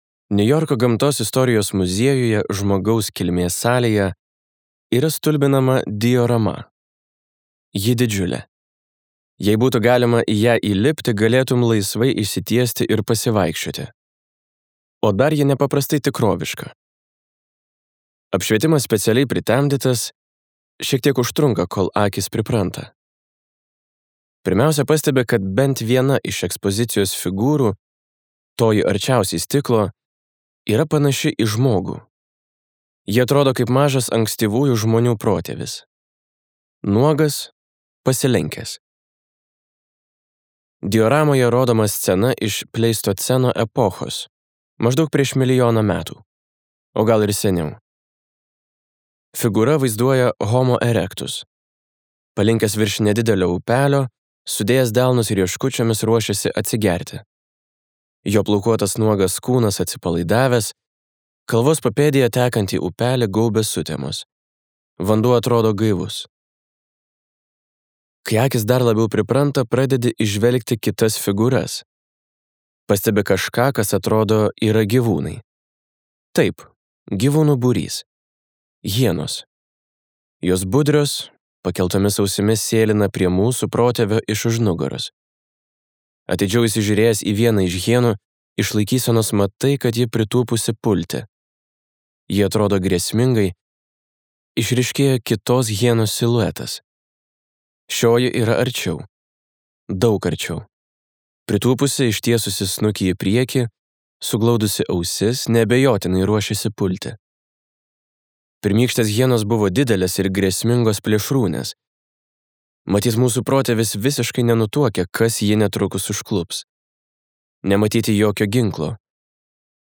Skaityti ištrauką play 00:00 Share on Facebook Share on Twitter Share on Pinterest Audio Atsparumo galia.